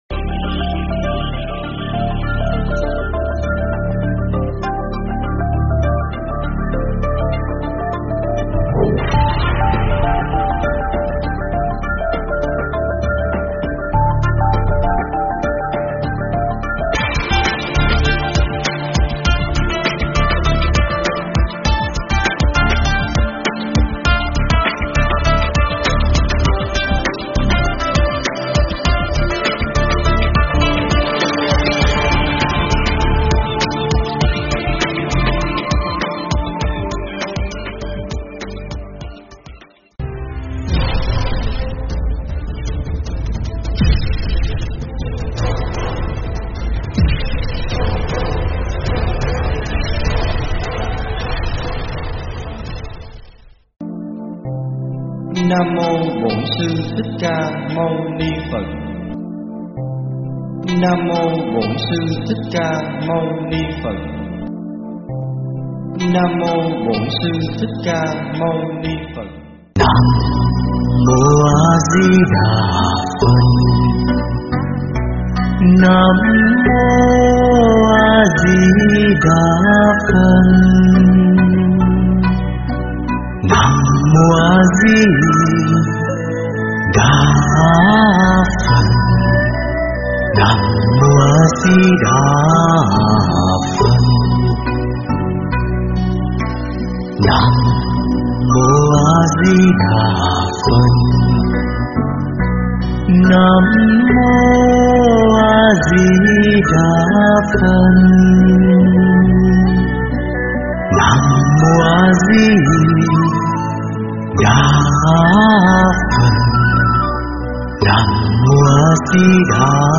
thuyết pháp
giảng tại chùa Phật Tổ (Úc)